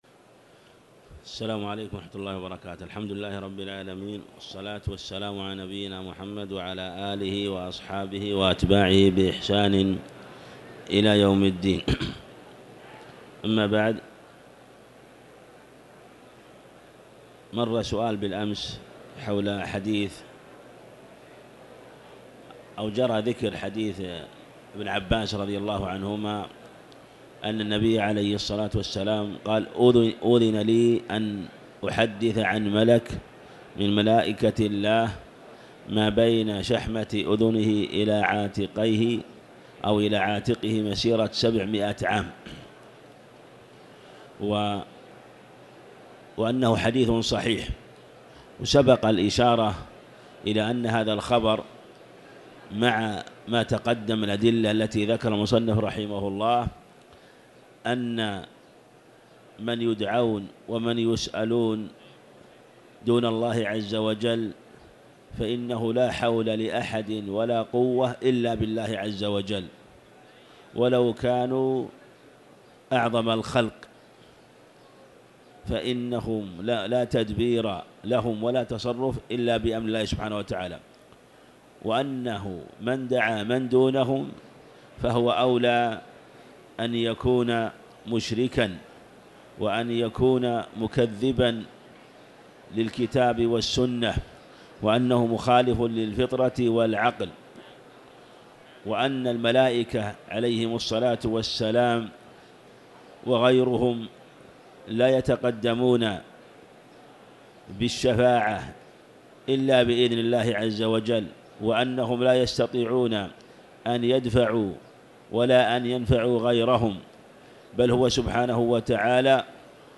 تاريخ النشر ١٩ رمضان ١٤٤٠ هـ المكان: المسجد الحرام الشيخ